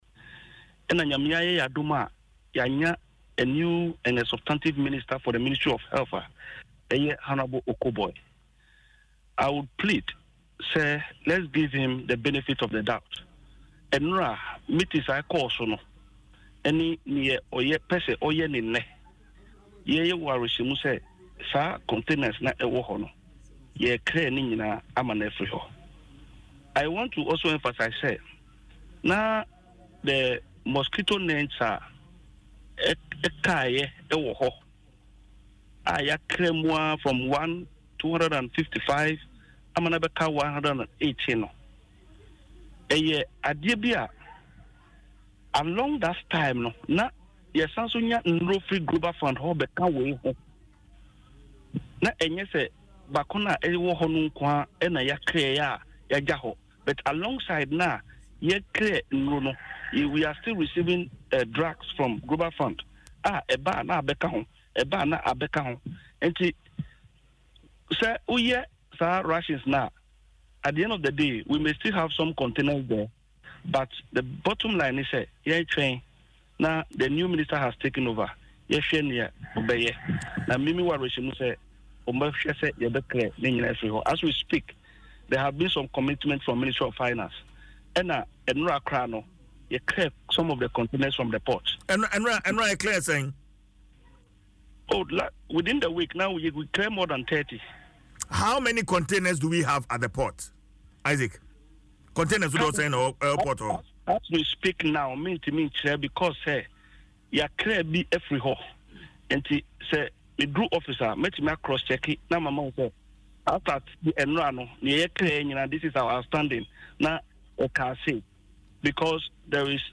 in an interview on Adom FM’s morning show, Dwaso Nsem said the Health Minister